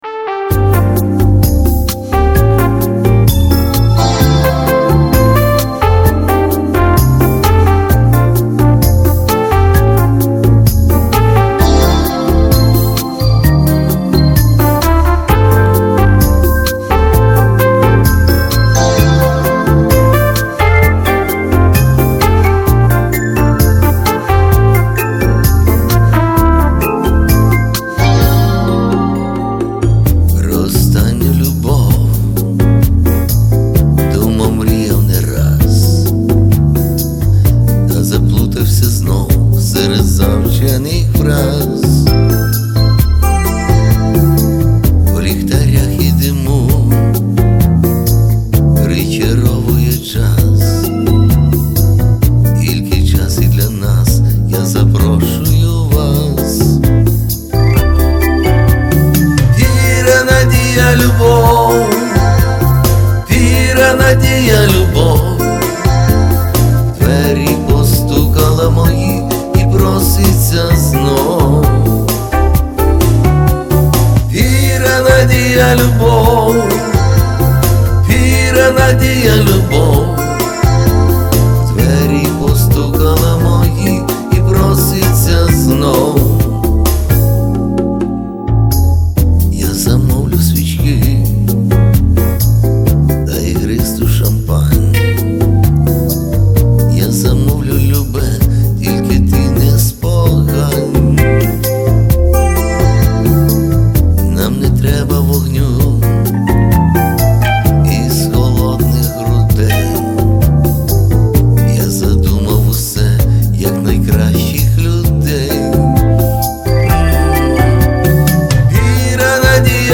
TC Helicon voicelive Touch мікрофон: TAK-55